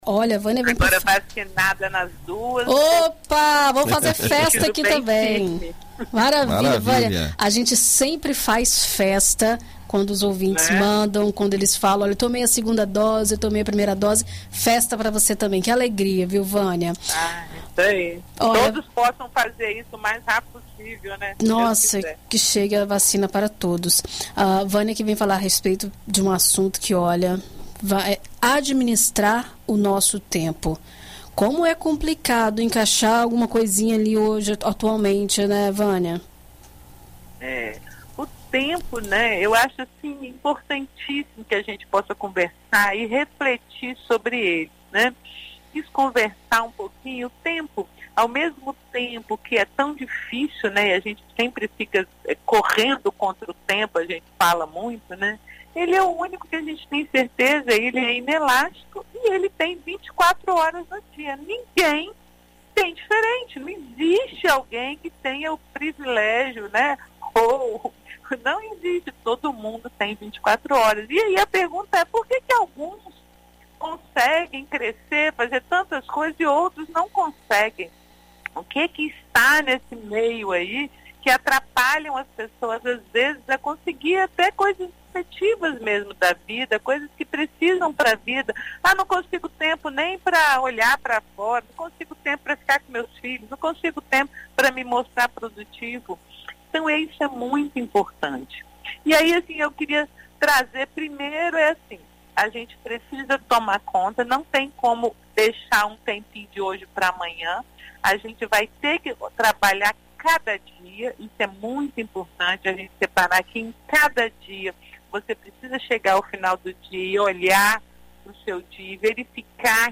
Na coluna Vida e Carreira desta quarta-feira (11), na BandNews FM Espírito Santo